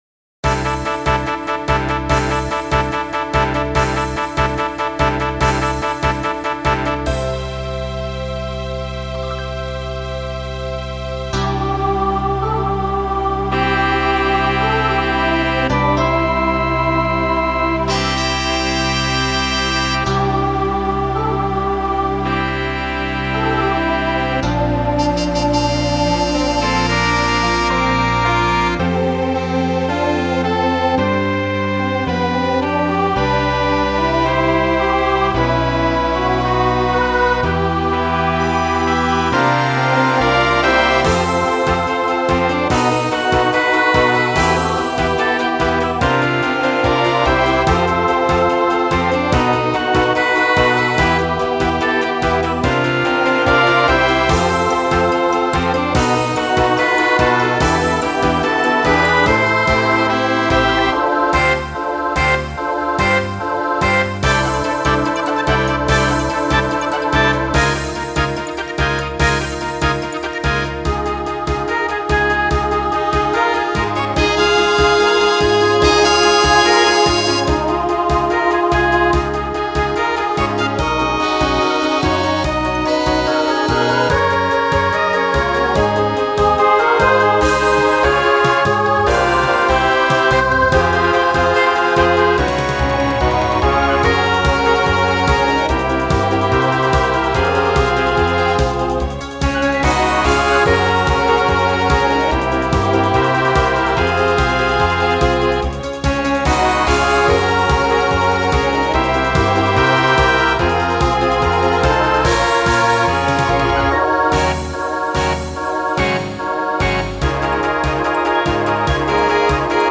Voicing SSA Instrumental combo Genre Broadway/Film